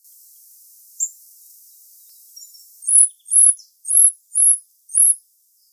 Regulus regulus - Goldcrest - Regolo
E 11° 16' - ALTITUDE: +130 m. - VOCALIZATION TYPE: two call types.
- COMMENT: This recording was made with an unattended automatic recorder. Note the very high pitch of the second call type (srri), more typical for Regulus regulus than for Regulus ignicapilla.